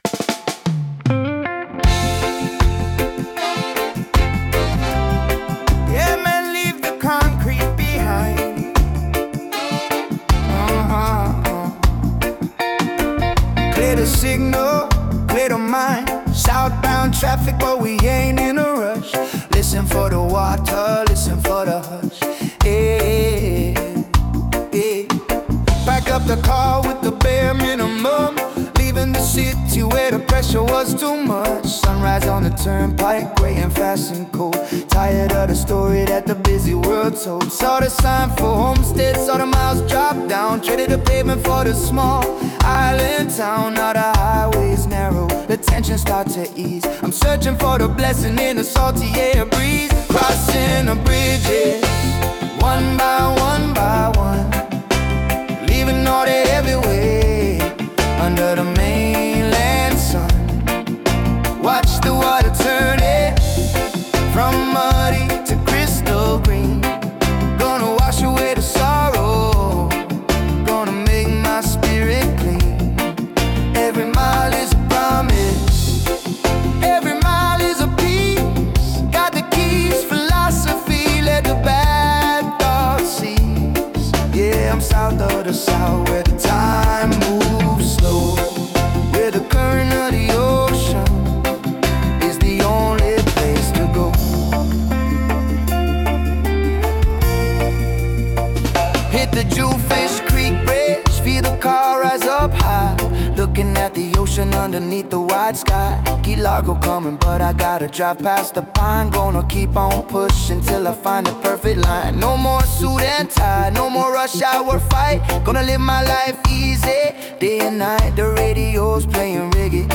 Here is a Reggae song I created using Suno